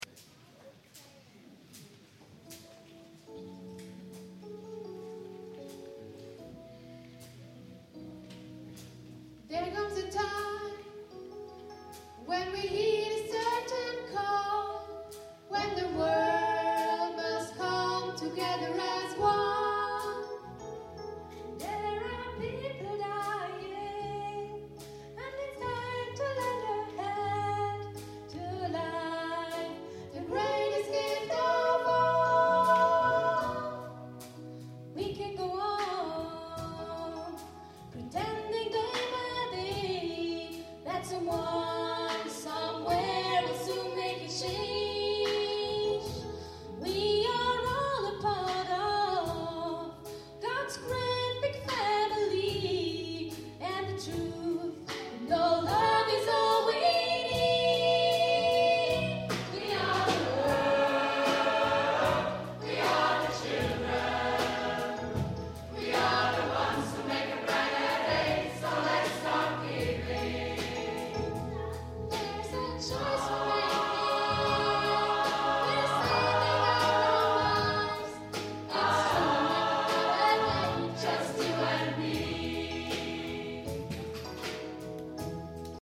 Live-Aufnahmen Konzert Schneisingen 2007
Live-Konzert vom 20./21. Januar 2007 in der kath. Kirche Schneisingen.